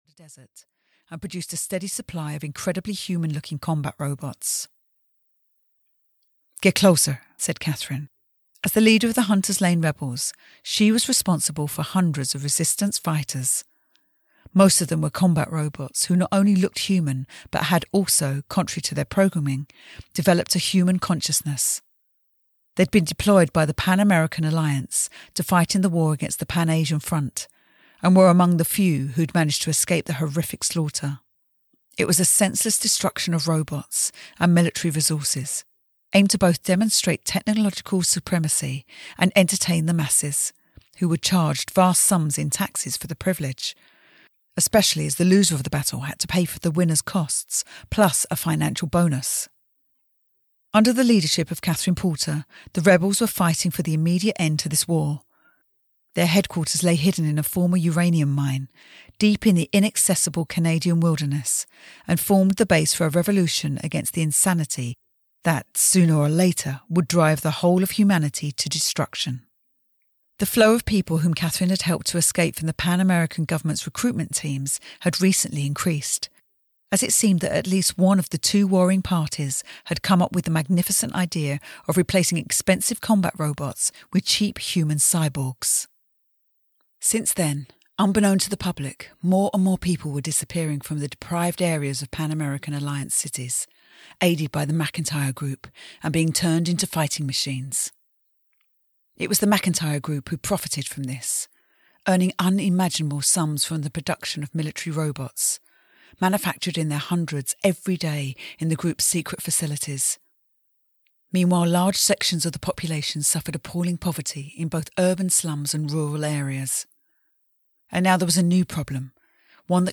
Robolove 3 - Operation: Silver Soul (EN) audiokniha
Ukázka z knihy